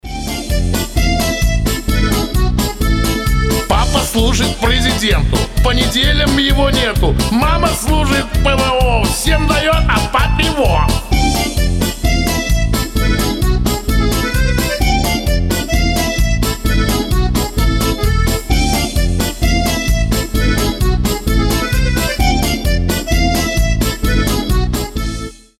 шансон
частушки , аккордеон
веселые